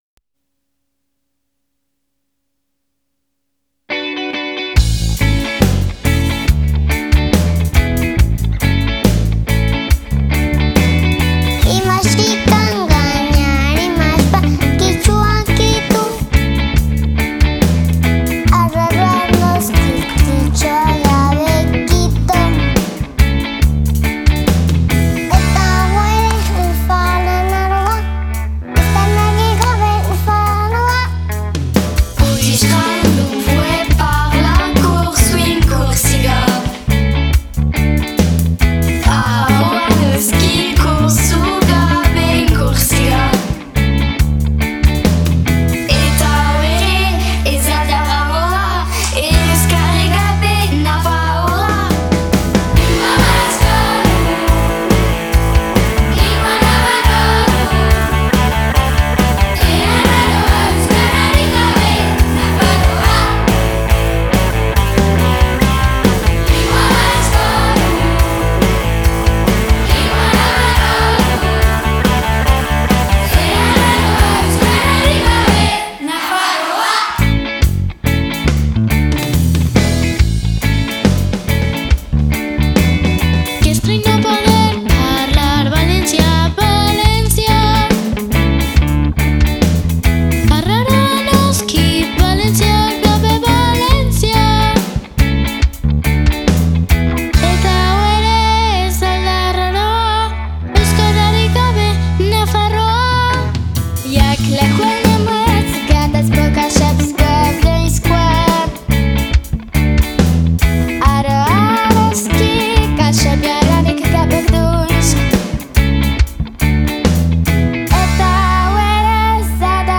Orduan, herrialde ezberdinetako pertsonek parte hartu zuten beraien hizkuntzan abestuz (alemanez, ingelesez, arabieraz, japonieraz…).